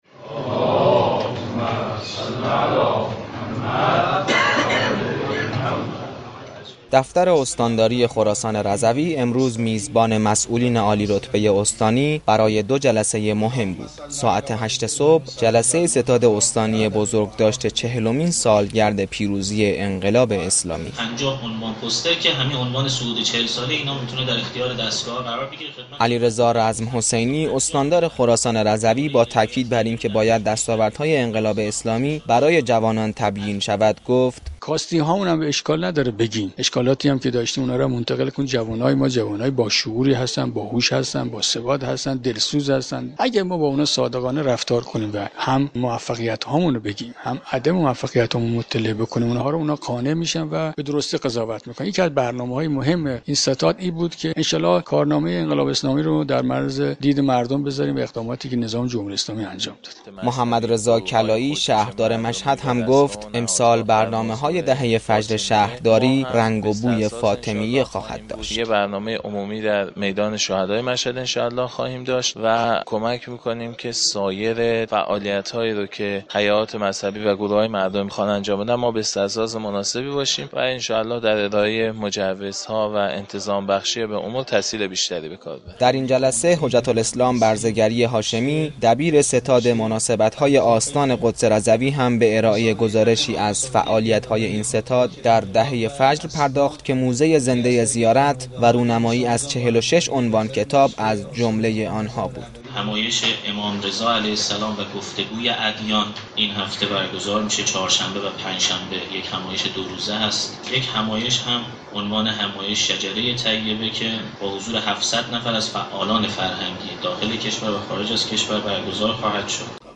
آیت الله سید احمد علم الهدی نماینده ولی فقیه در خراسان رضوی در جلسه شورای فرهنگ عمومی این استان گفت : هویت و شان حرم امام هشتم(ع) باید در مشهد حفظ شود.
علیرضا رزم حسینی استاندار خراسان رضوی در ستاد استانی بزرگداشت چهلمین سالگرد پیروزی انقلاب اسلامی گفت:امروز مهمترین مخاطب اصلی ما، جوانان هستند که باید دستاوردهای انقلاب اسلامی برایشان تبیین شود.